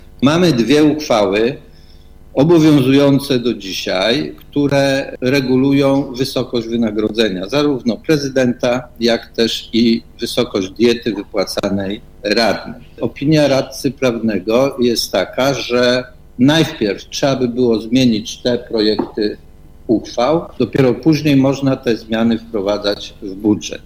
Mówi Włodzimierz Szelążek – przewodniczący rady miasta.